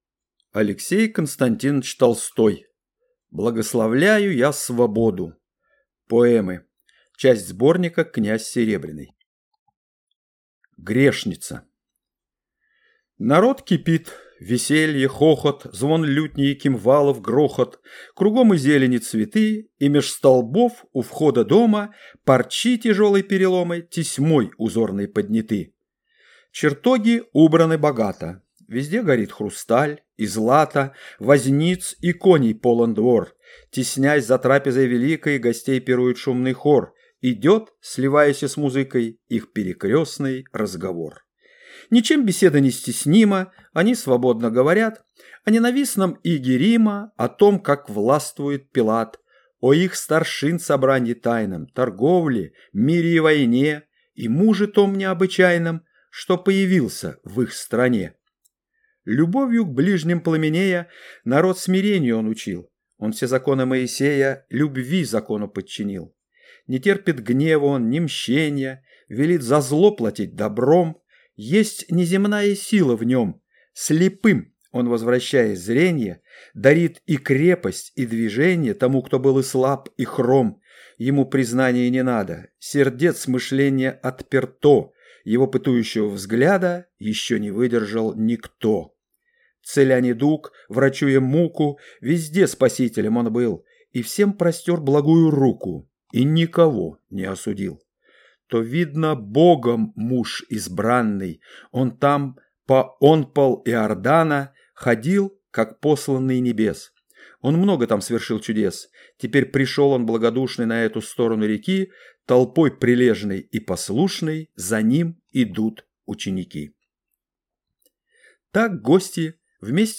Аудиокнига Благословляю я свободу (поэмы) | Библиотека аудиокниг